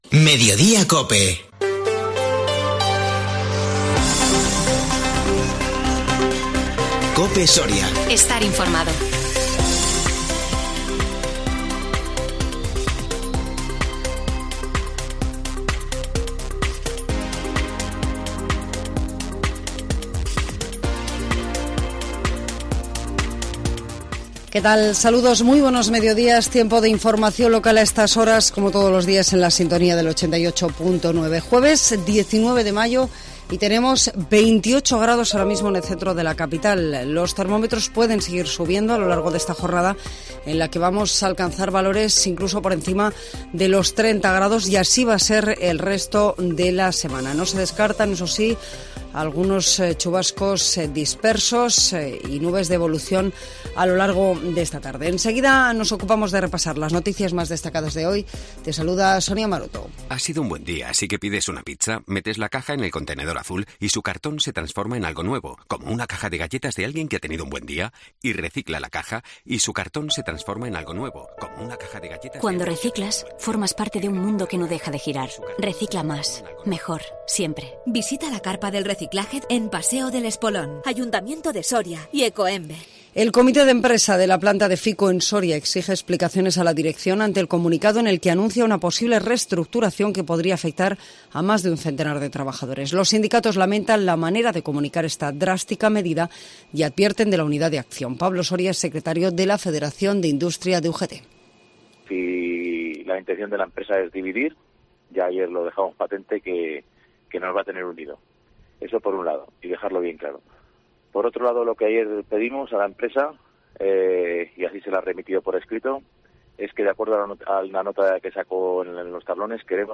INFORMATIVO MEDIODÍA COPE SORIA 19 MAYO 2022